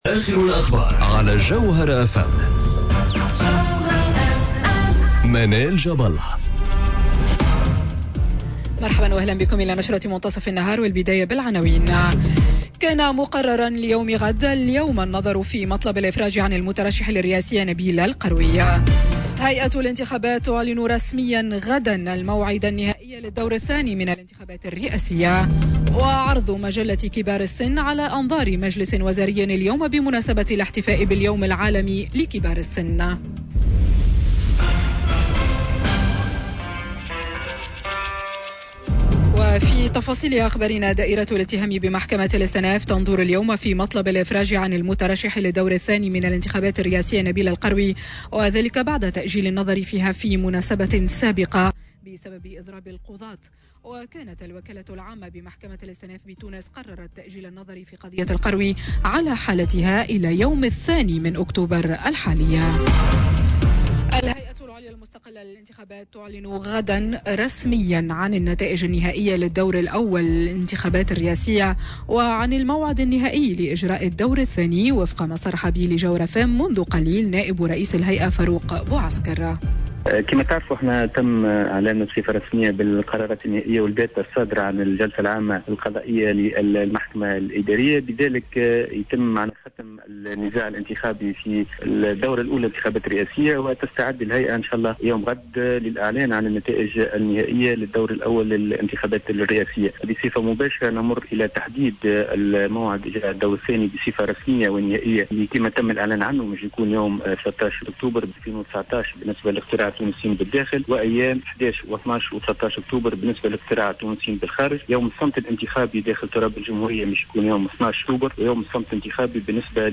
Journal info 12h00 de mardi 01 Octobre 2019